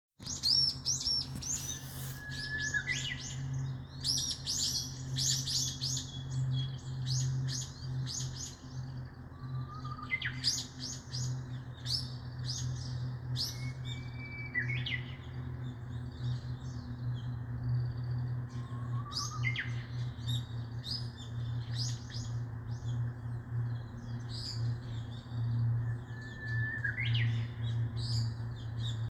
제주휘파람새 소리(집에서).mp3
휘파람 소리랑 진짜 비슷하다.
그때를 위해서 집에서 들리는 휘파람새 소리를 녹음한다.